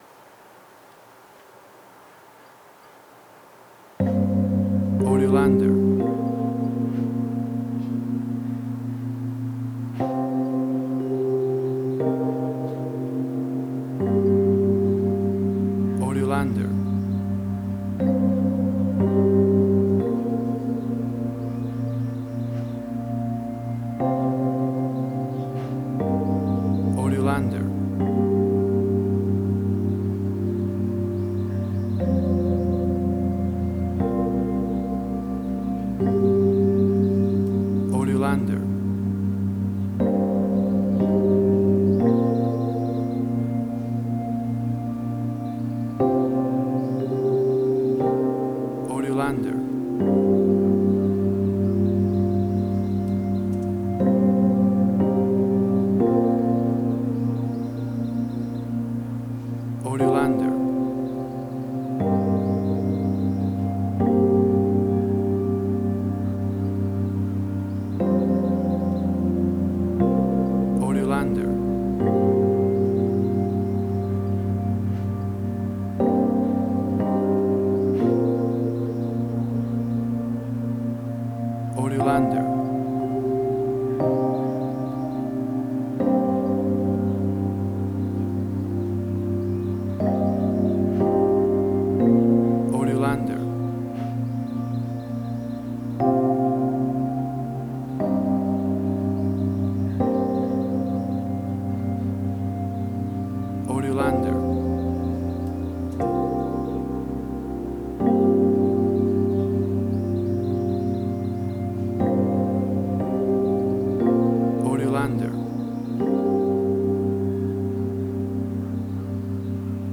Suspense, Drama, Quirky, Emotional.
Tempo (BPM): 120